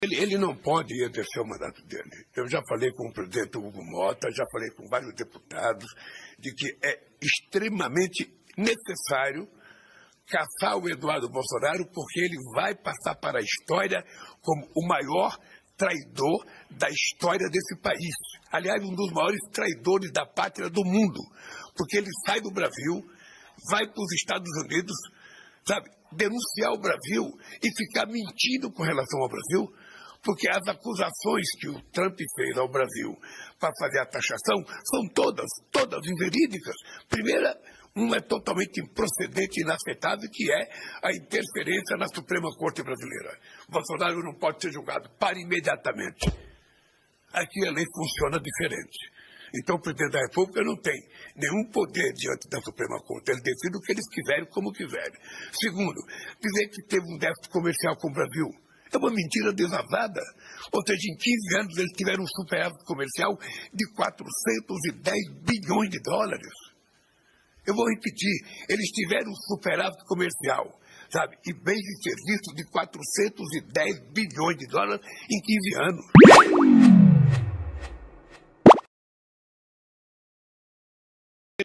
Em entrevista à Rádio Itatiaia, o presidente afirmou que ações como essa reforçam a necessidade de respeito às instituições e que o Congresso deve avaliar a situação com rigor. Lula destacou ainda que o foco de seu governo permanece na defesa da democracia e na preservação da ordem legal no país.